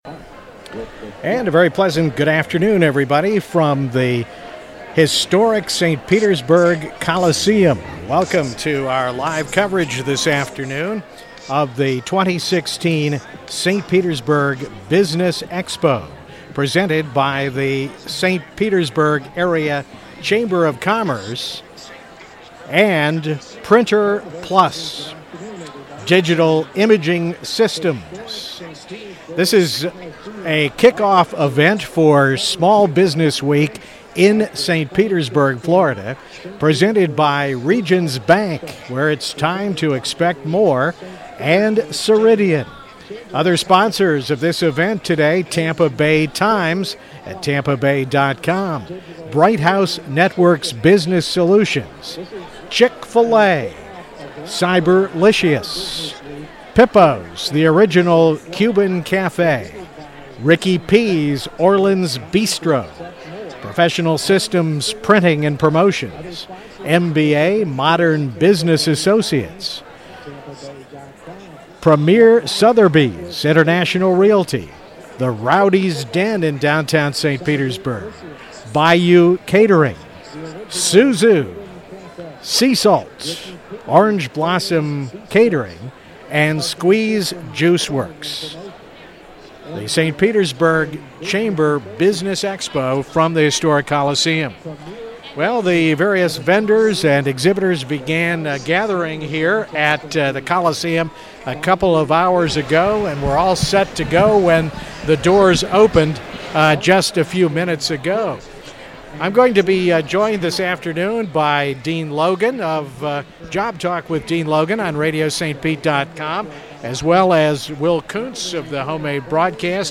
St. Pete Area Chamber "Small Business Expo" 5-3-16 Short Portion of 3 Hour Remote Broadcast